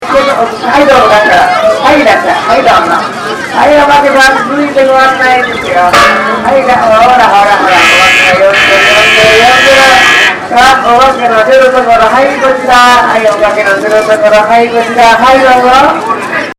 どうも、客寄せの喋りが面白いんじゃないかと気づきまして、
祭りの中MacBookを持って録音をしてきました
予想以上に内臓マイクの音質が良かったのですが、
独特のテンションが伝わってくるとうれしいです。